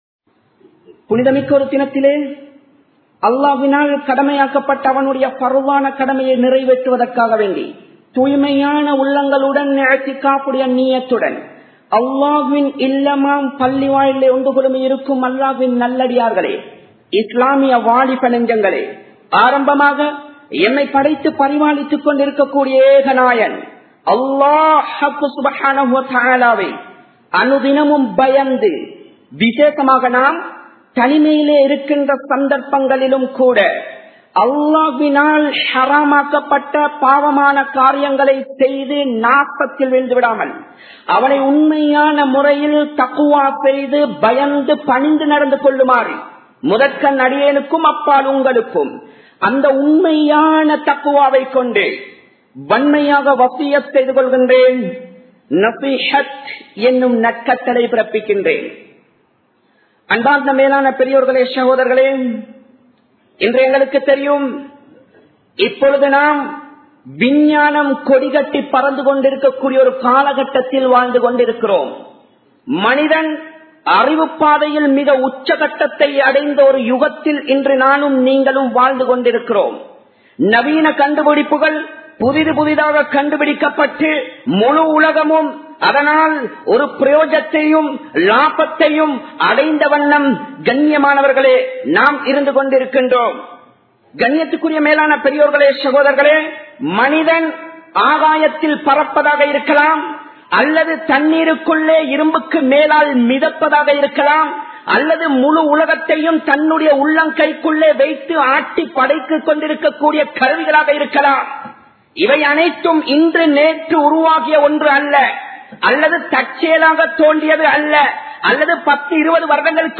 Colombo 11, Samman Kottu Jumua Masjith (Red Masjith)